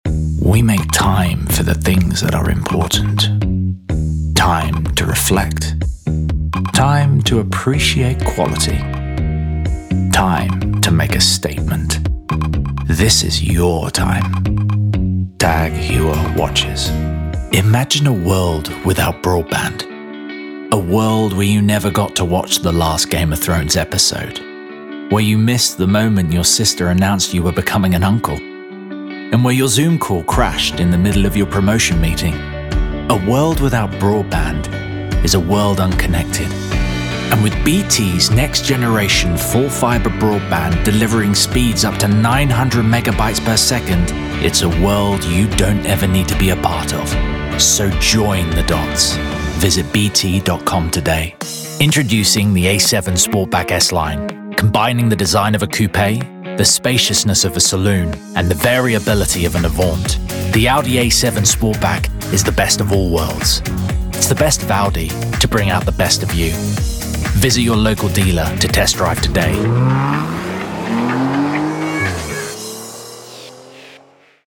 Voice Reel
Commercial reel